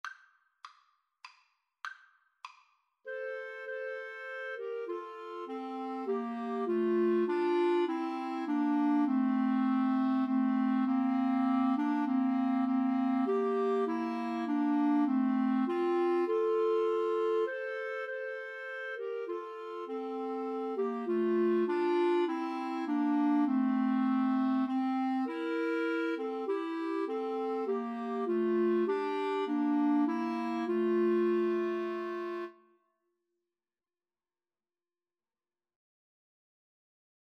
3/4 (View more 3/4 Music)
Clarinet Trio  (View more Easy Clarinet Trio Music)